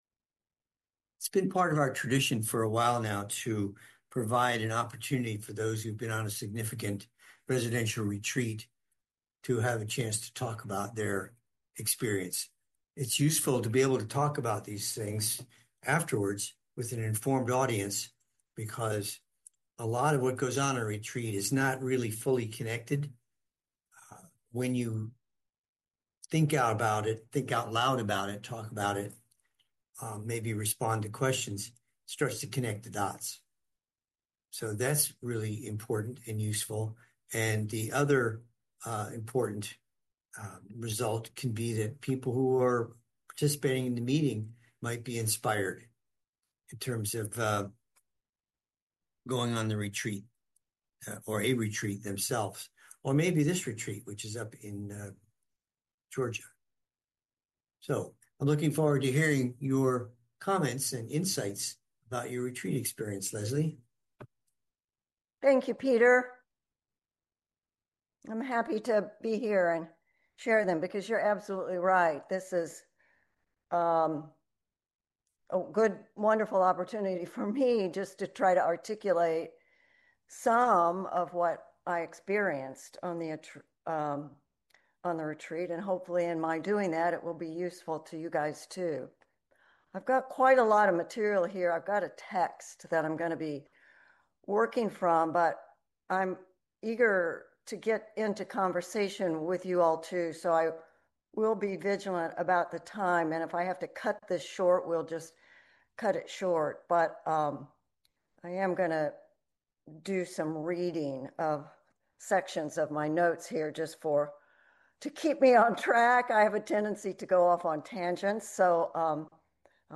She describes the structure of the retreat and some significant insights she realized about herself. Her presentation is followed by several comments and questions from participants in the meeting.